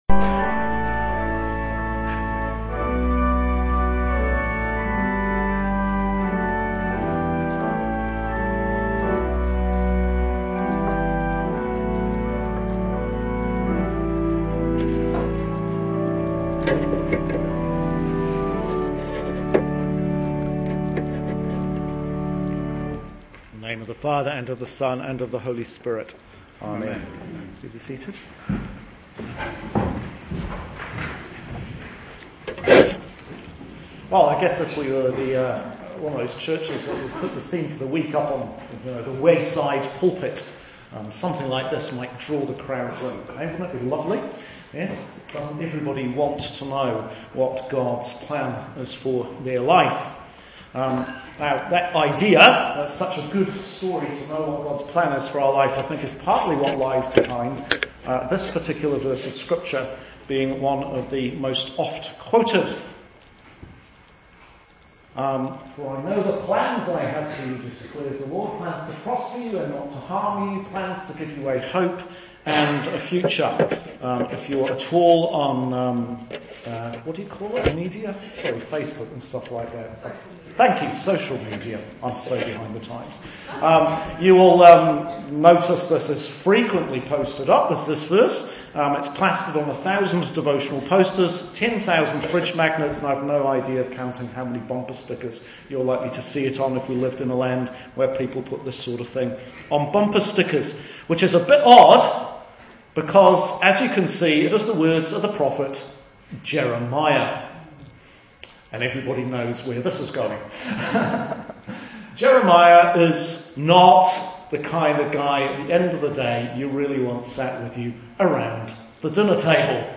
Sermon for 22nd Sunday in Ordinary Time – Year B